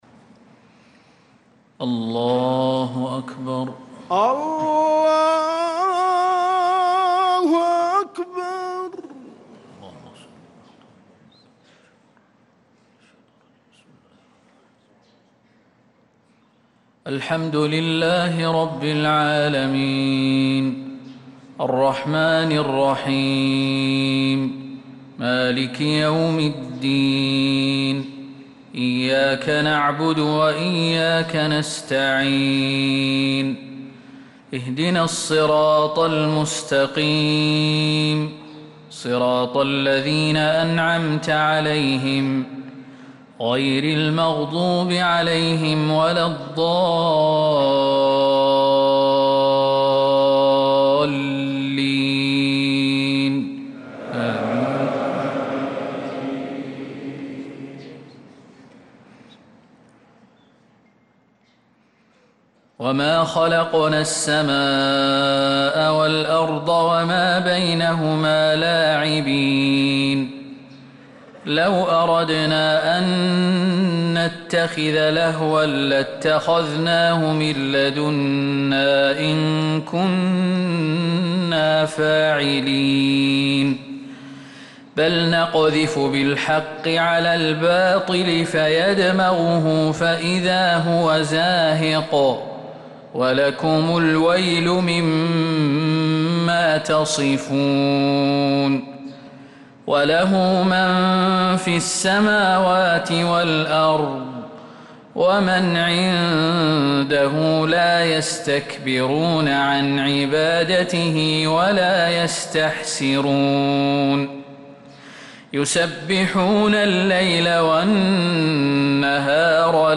صلاة الفجر للقارئ خالد المهنا 24 ربيع الأول 1446 هـ